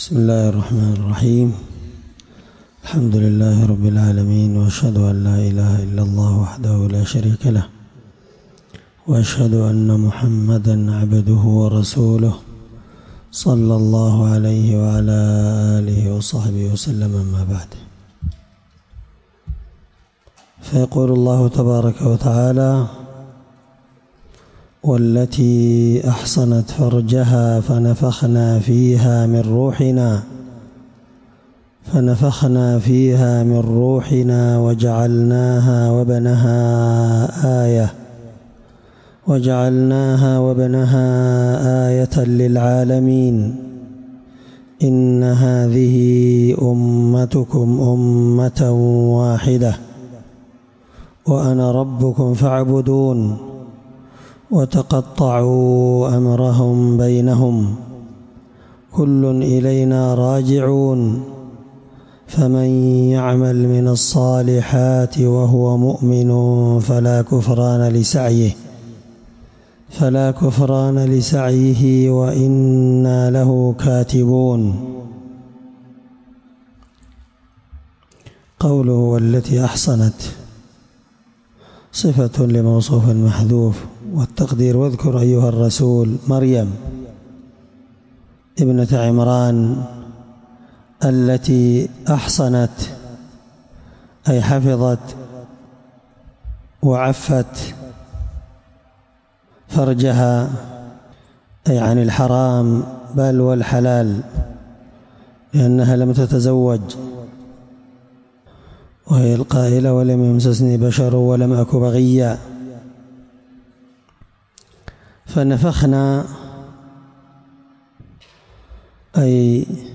21سورة الأنبياء مع قراءة لتفسير السعدي